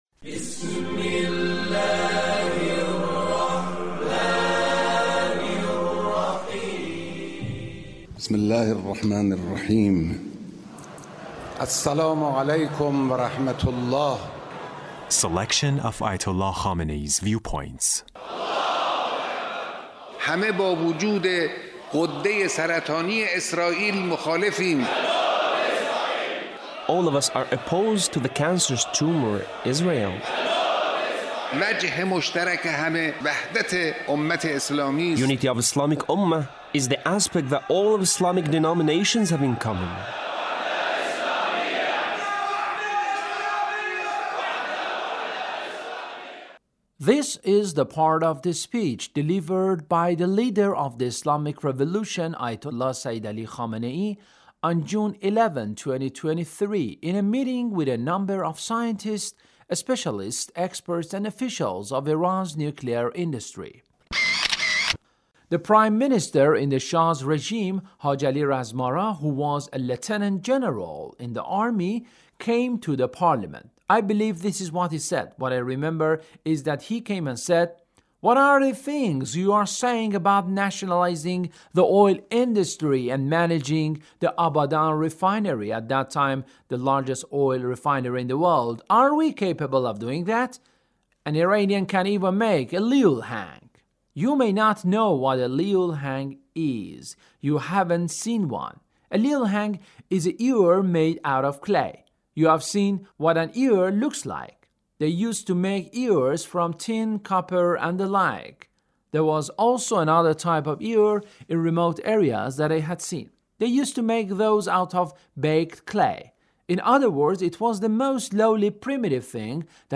Leader's Speech (1790)
Leader's Speech in a meeting with a number of scientists,and officials of Iran’s nuclear industry